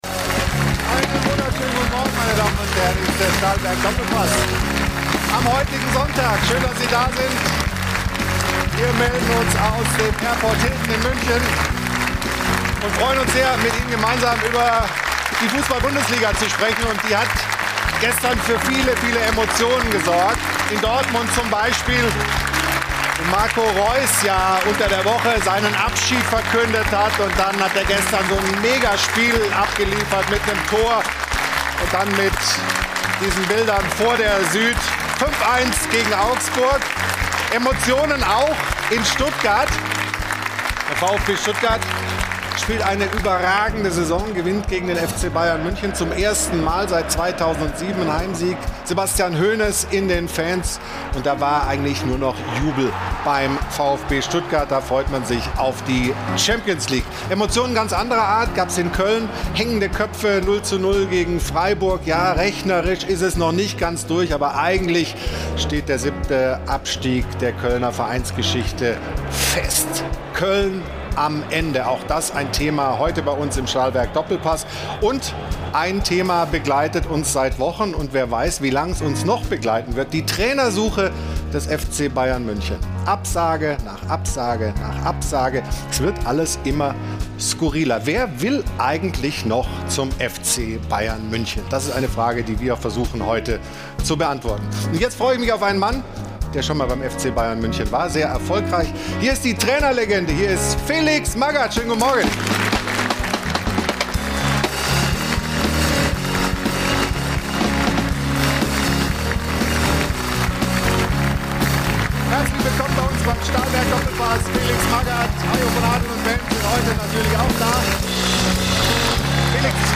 In der neuen Folge des Doppelpass diskutieren sie unter anderem über die Themen: 00:00 Über die Situation bei der Trainersuche des FC Bayern 27:46 Über die Rotation des FC Bayern gegen Stuttgart mit Blick auf das Rückspiel in Madrid 48:15 Hat Felix Magath wirklich mit Uli Hoeneß telefoniert?